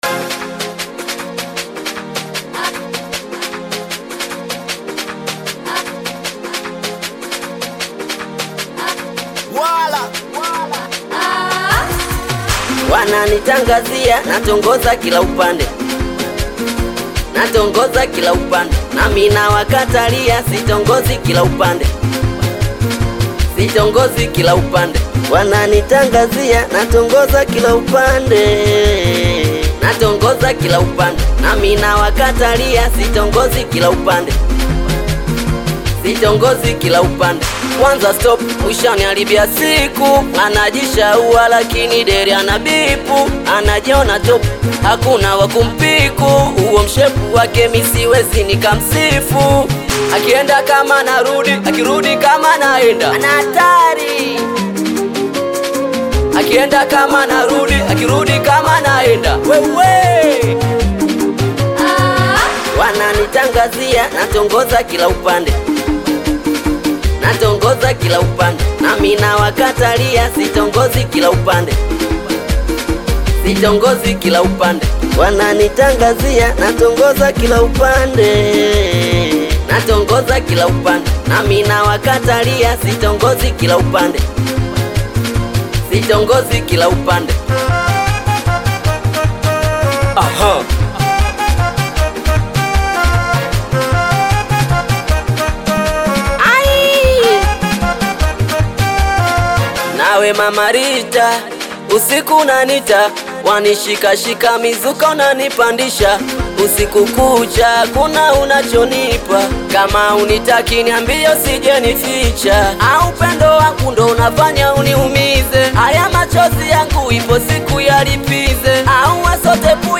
Audio Latest Singeli